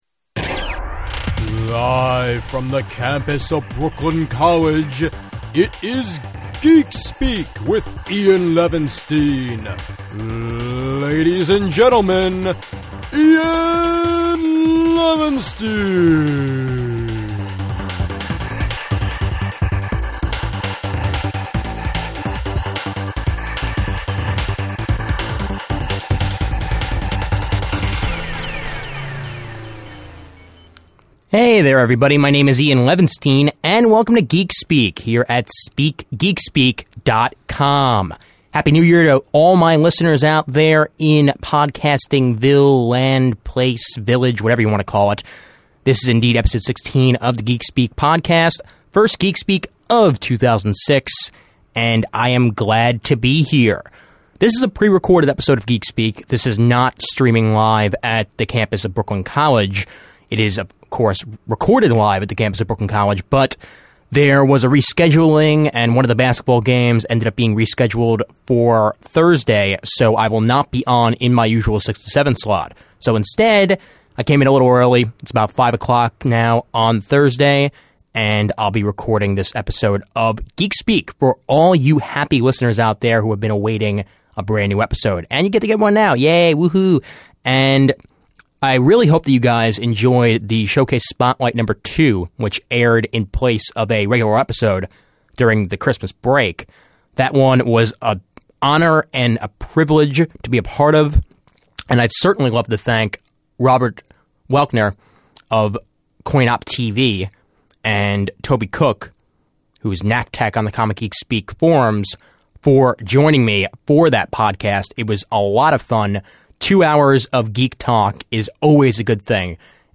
This epsidoe was recorded at the Brooklyn College Radio Studio on Thursday, January 26th, 2006.
I’m sure you’re all as glad as I am to have a Geekspeak back in the studio, and you should expect this to be the norm for many episodes to come.